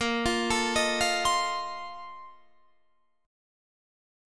A#M7sus2sus4 Chord
Listen to A#M7sus2sus4 strummed